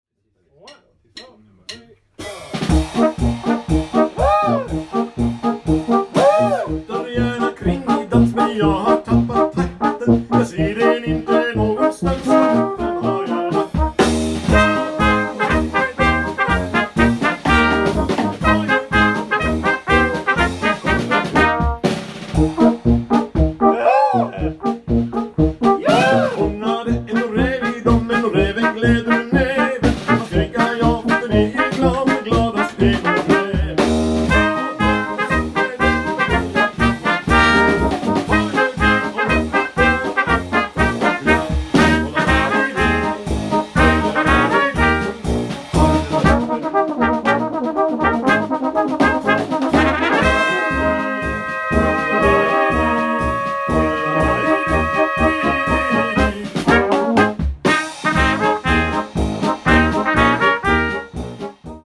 • Balkan/Ompa-ompa